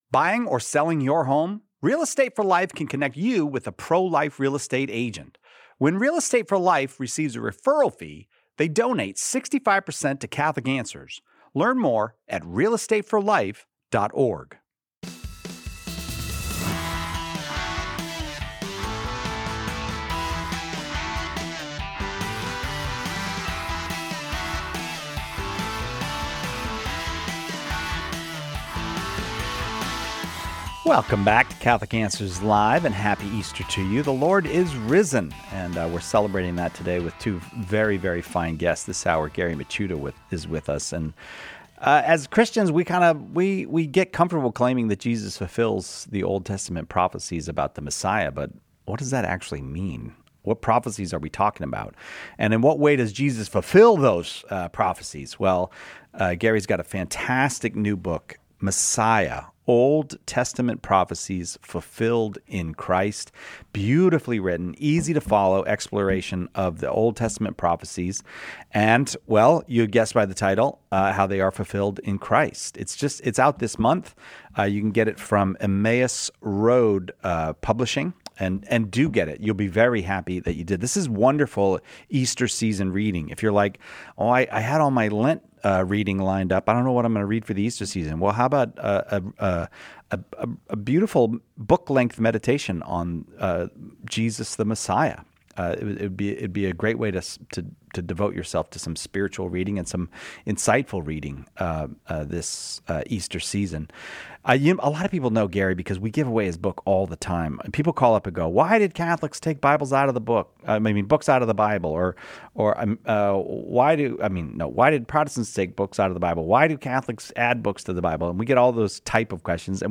In this episode of Catholic Answers Live , Catholic Answers apologists explore the Old Testament foundations of the Messiah and how Jesus fulfills them. They discuss the most important messianic texts and passages, address whether people in Jesus’ time were sincerely seeking the Messiah, and examine common objections—such as why Jesus didn’t immediately bring universal peace.